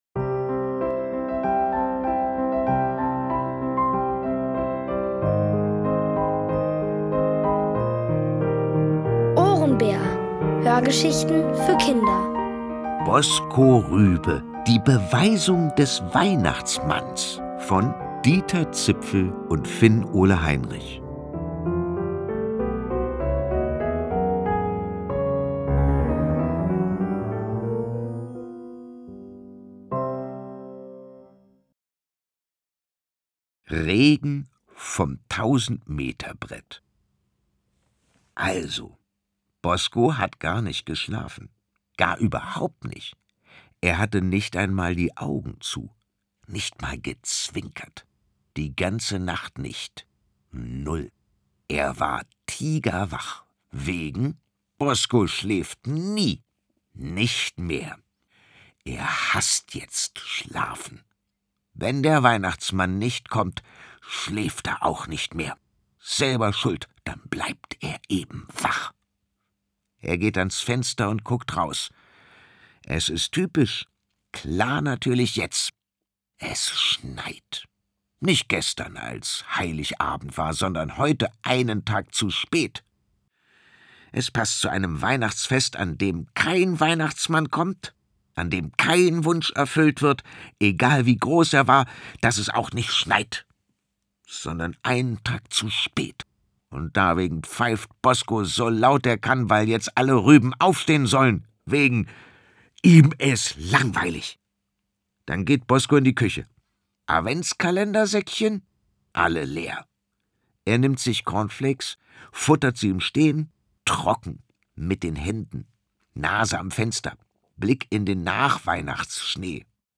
Erzählt von Boris Aljinovic.